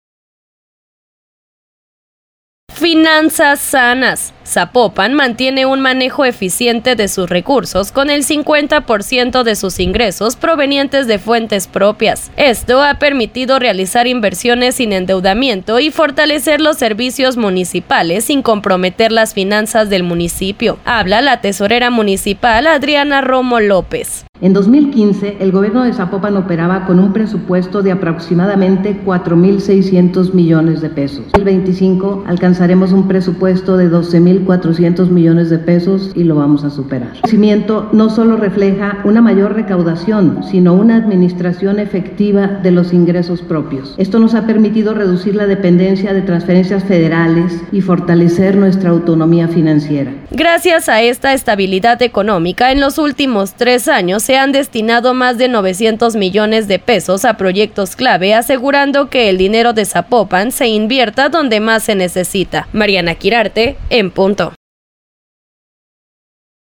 Habla la tesorera municipal Adriana Romo López.